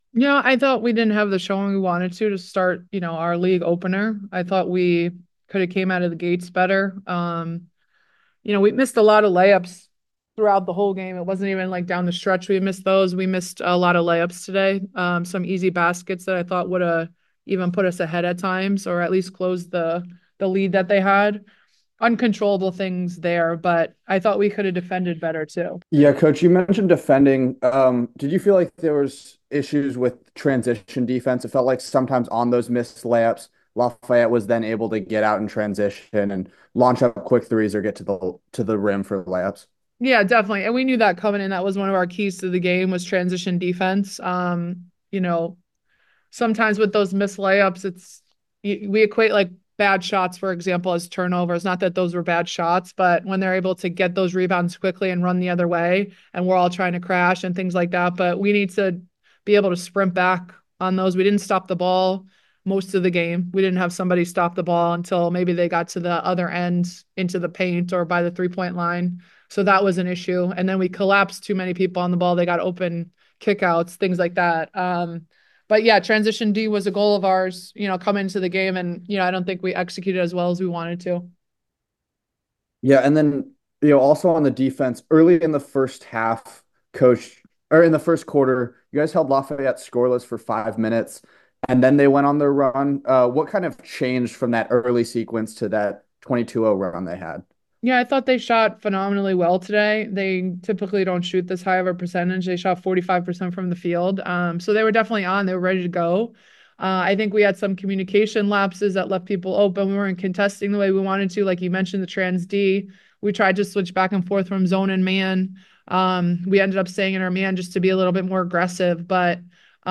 WBB_Lafayette_Postgame.mp3